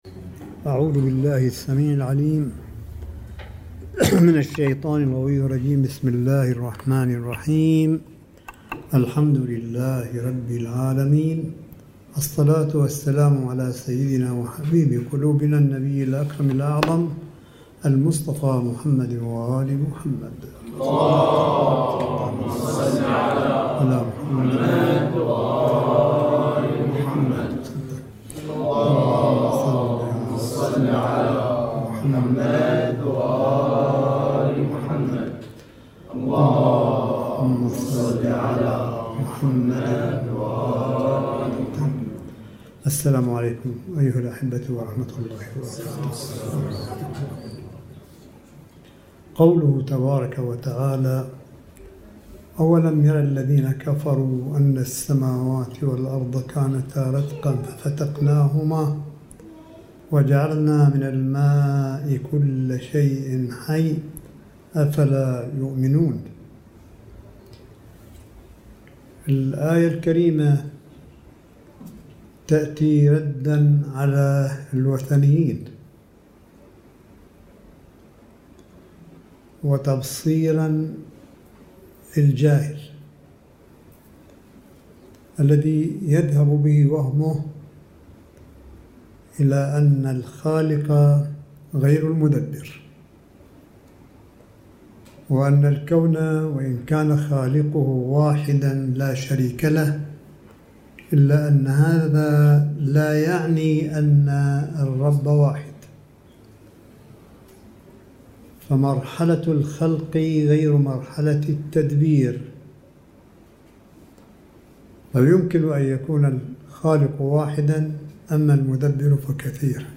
ملف صوتي للحديث القرآني لسماحة آية الله الشيخ عيسى أحمد قاسم حفظه الله بقم المقدسة – 27 شهر رمضان 1440 هـ / 02 يونيو 2019م